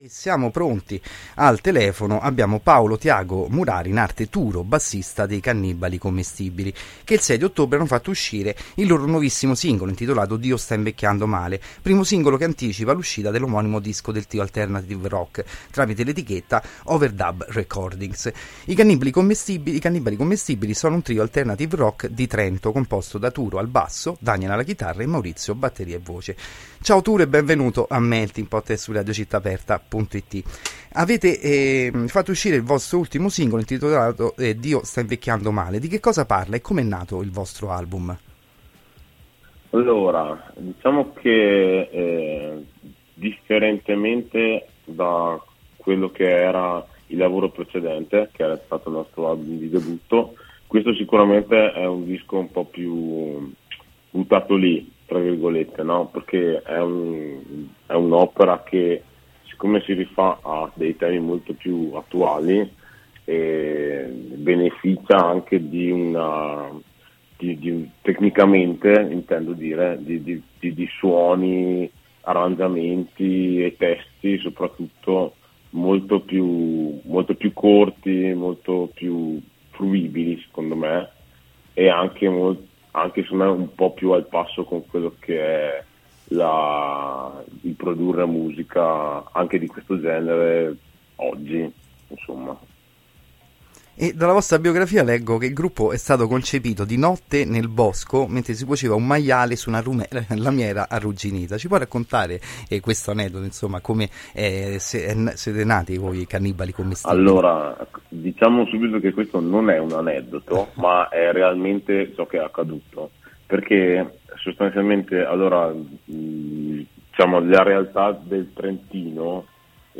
Intervista-cannibali-commestibili.mp3